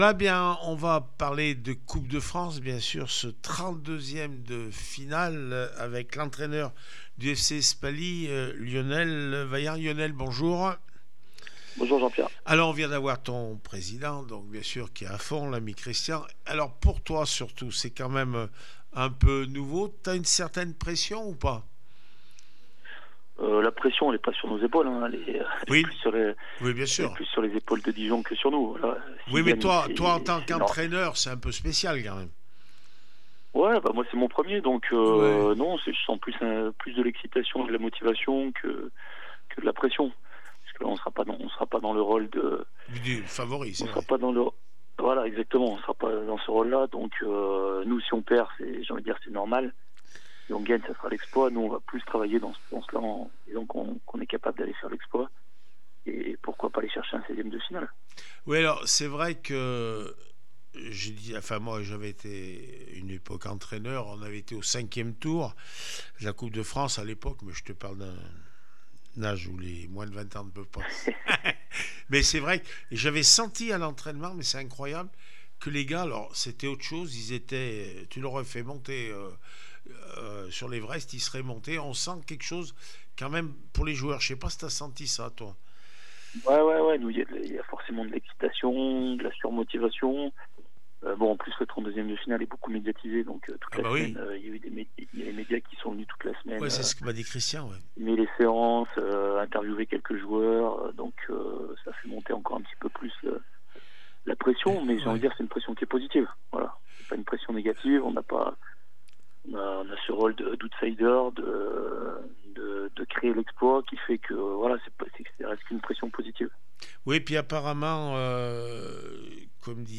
19 décembre 2024   1 - Sport, 1 - Vos interviews